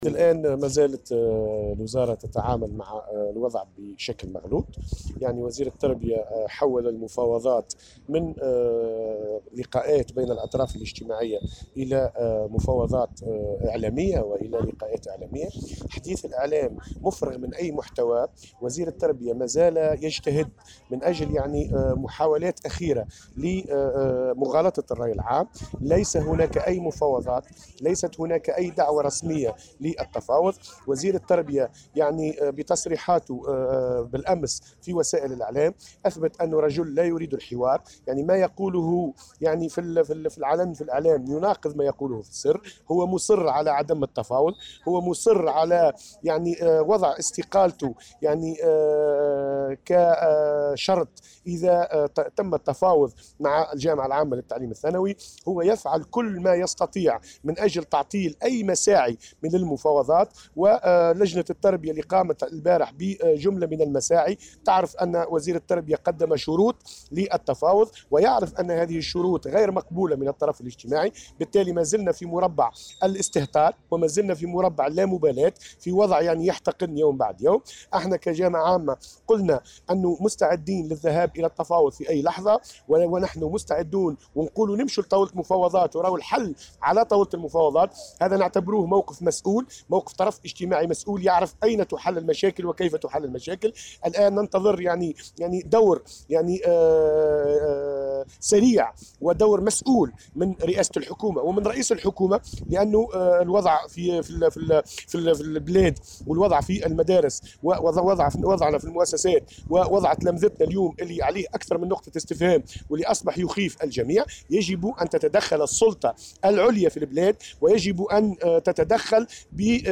وقال في تصريح لمراسل "الجوهرة اف أم" على هامش يوم غضب جهوي ينظمه اليوم الأساتذة، إن الوزير مازال يتعامل مع الوضع بشكل مغلوط وحوّل المفاوضات الاجتماعية إلى مفاوضات إعلامية مفرغة من أي محتوى ومغالطة للرأي العام، وفق تعبيره.